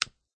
light_click_3.ogg